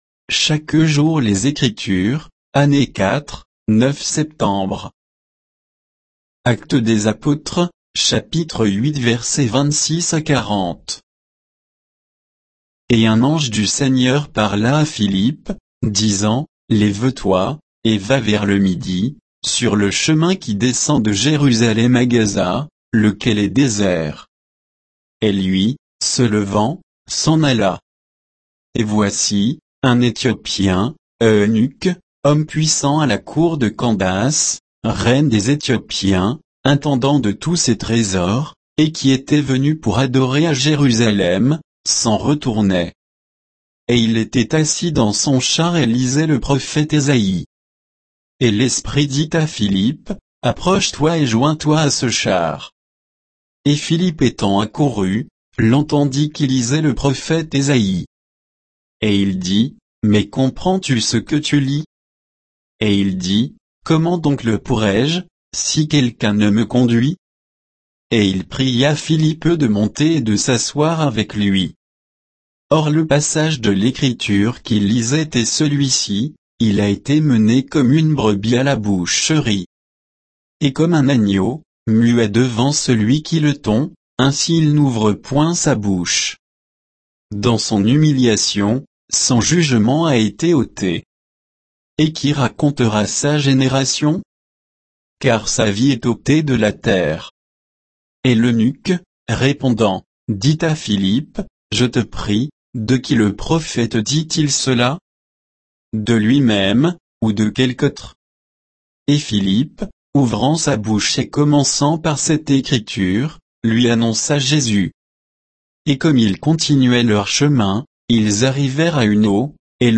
Méditation quoditienne de Chaque jour les Écritures sur Actes 8, 26 à 40